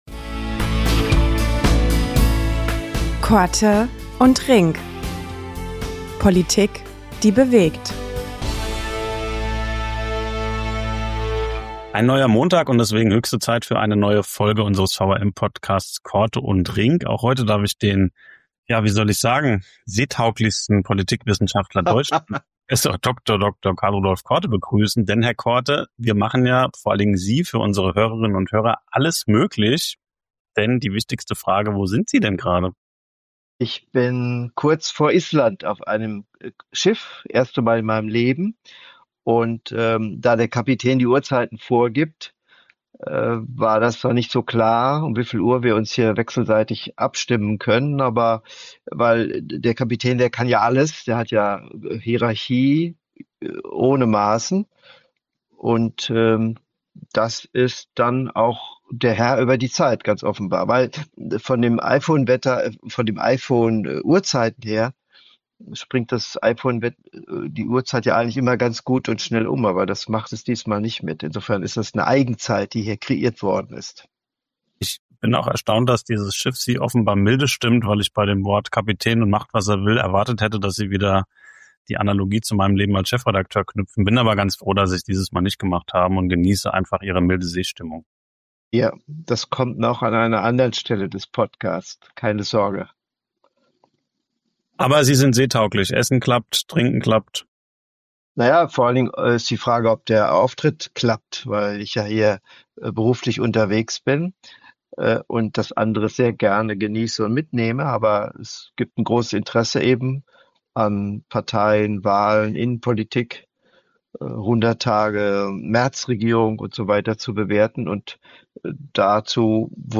Zugeschaltet aus den kühlen Gewässern vor Island, wo er auf politischer Vortragsreise unterwegs ist, analysiert Politikwissenschaftler Prof. Dr. Karl-Rudolf Korte die Kommunalwahl in Nordrhein-Westfalen.